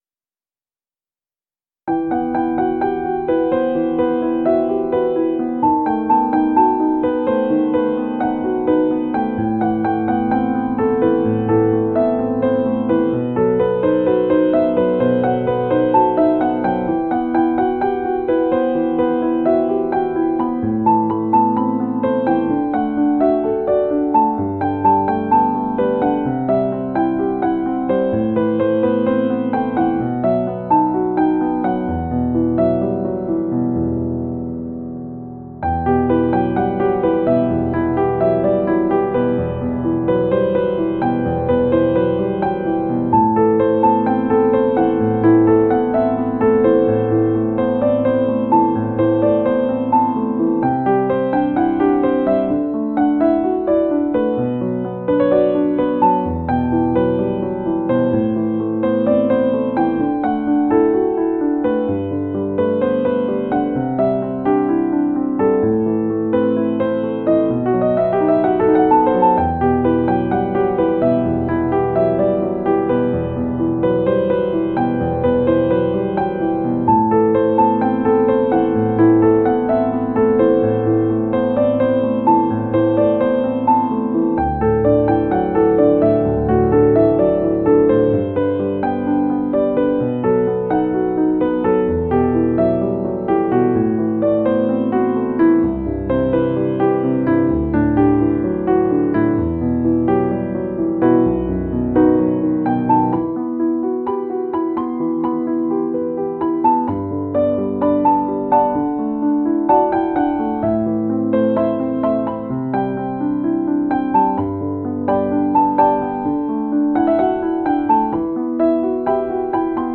نوع آهنگ: لایت]